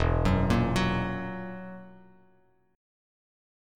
Esus2b5 chord